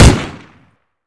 mac10-1.wav